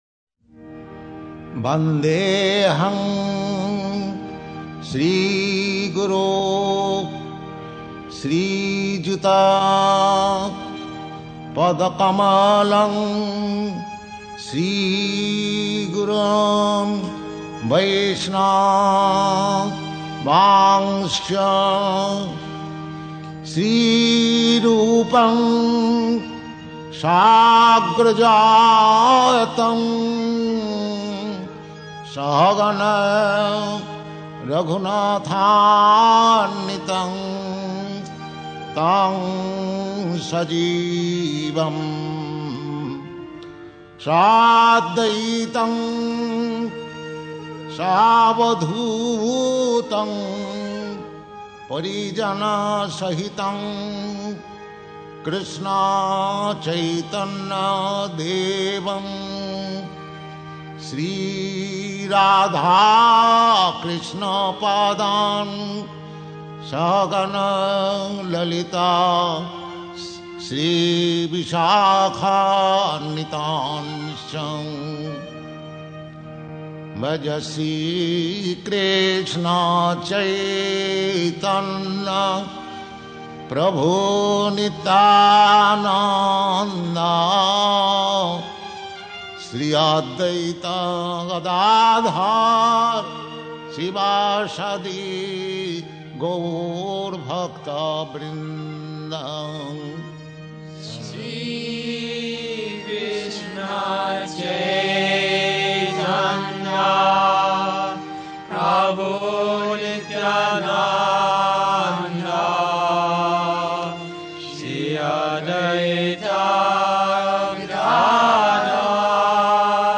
Vande ham (Hare Krsna Kirtana) - ISKCON Mayapur
CD_15-12__Vande_ham_Prayers_Kirtana__Purport.mp3